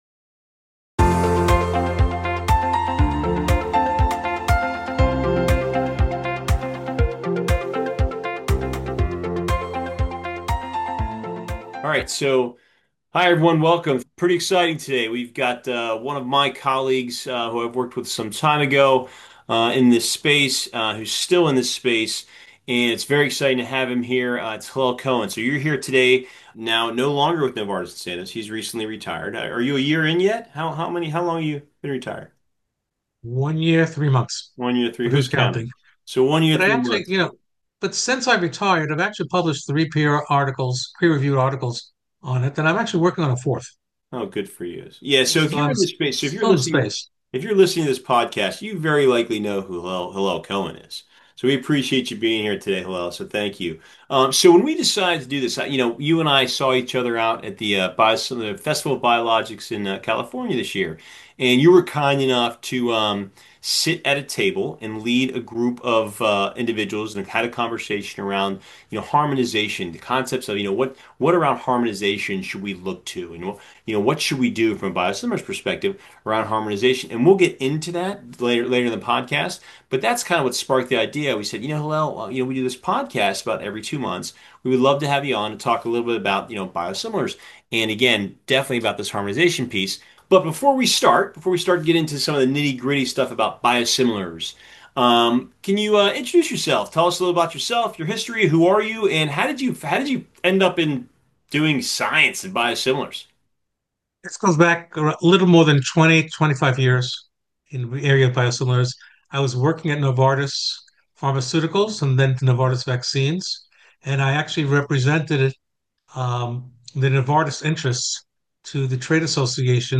The conversation covers the scientific and regulatory landscape, touching on the progress in streamlining biosimilar development, the importance of following scientific advancements, and the potential for harmonization in global health regulations.